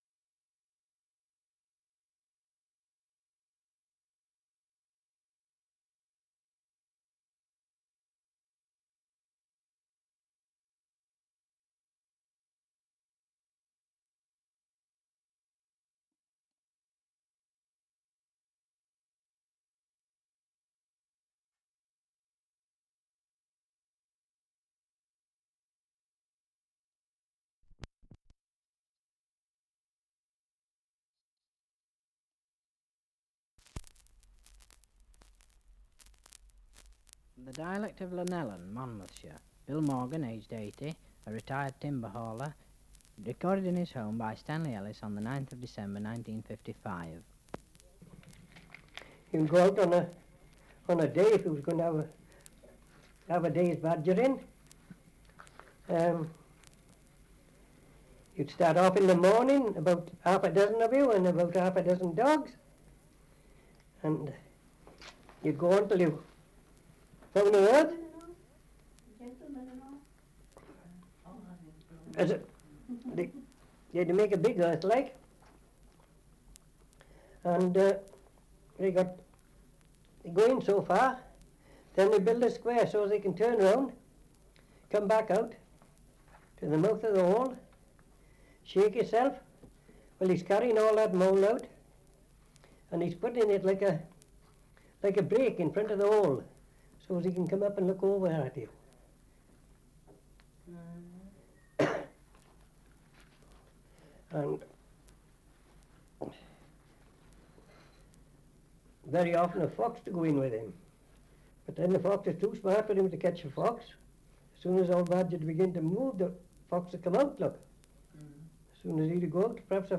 Survey of English Dialects recording in Llanellen, Monmouthshire
78 r.p.m., cellulose nitrate on aluminium